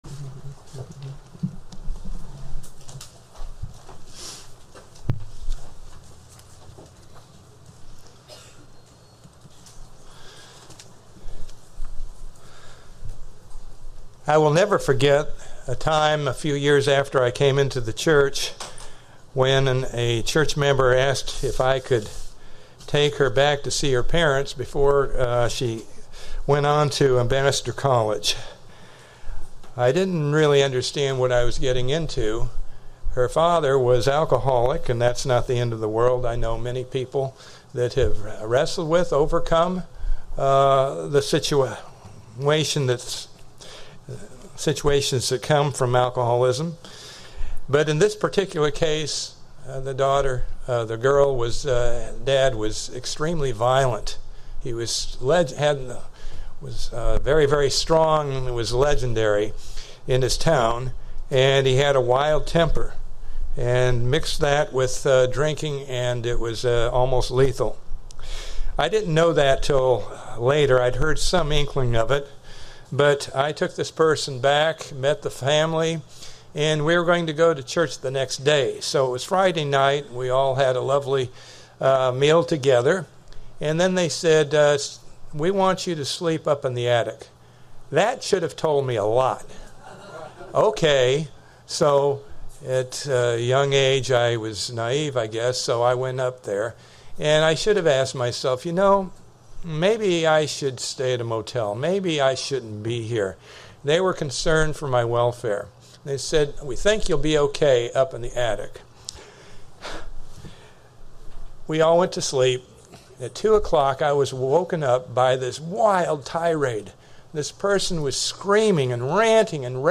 Given in Ft. Myers, FL Tampa, FL